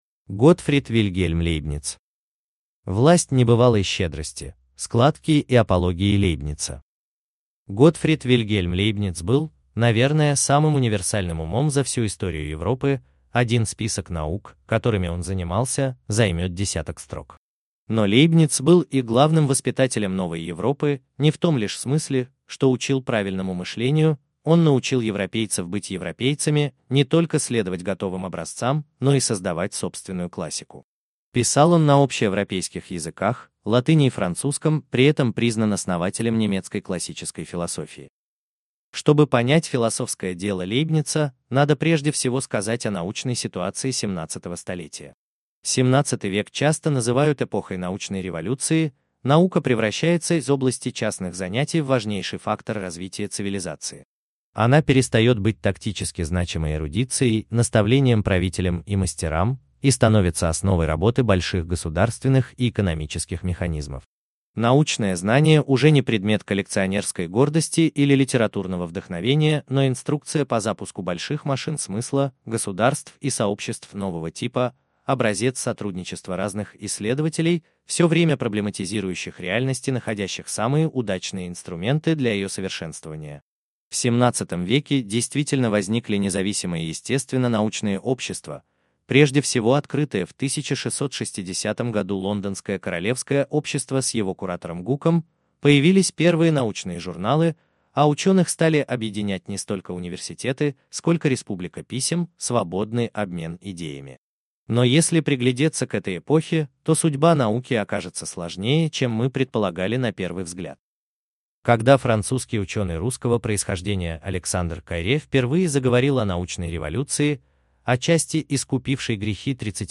Аудиокнига Монадология | Библиотека аудиокниг